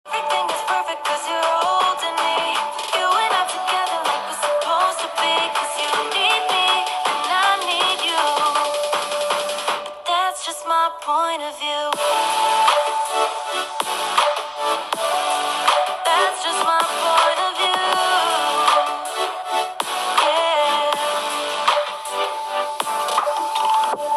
Xperia 10 IVは、モノラルスピーカーを搭載。
▼Xperia 10 IVのモノラルスピーカーの音はこちら！
モノラルスピーカーとしては、十分クリアで良い音が出ています。
Xperia10IV-Speaker.m4a